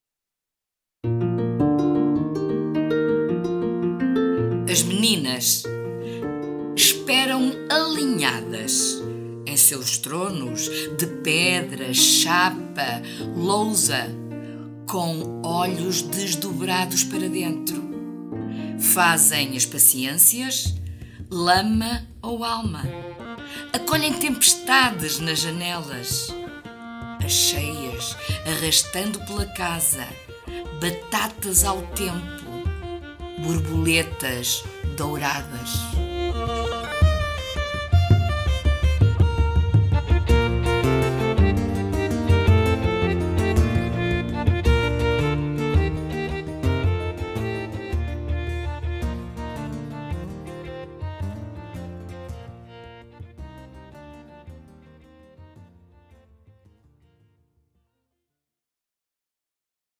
Música: “Zabelinha”, tradicional portuguesa, in Canções Tradicionais, licença Cantar Mais.